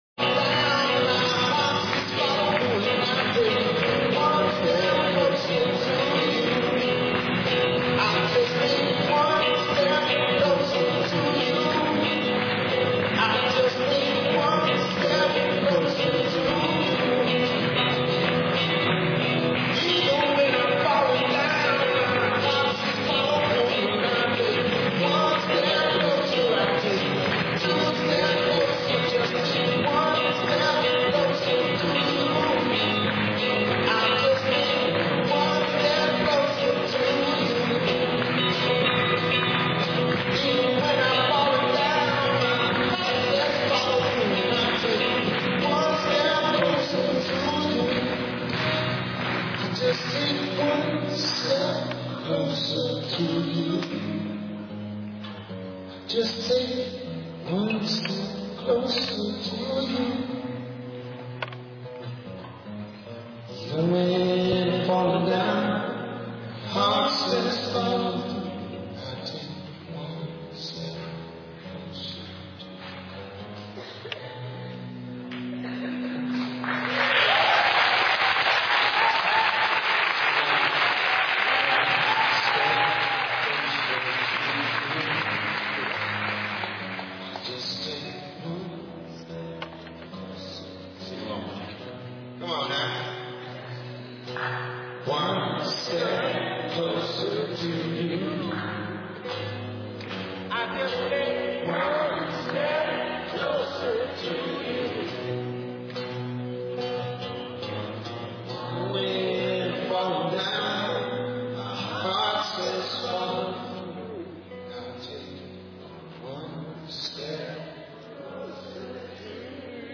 Three speakers from the April 30, 2005 rally in San Francisco, CA, sponsored by the International ACTION Coalition.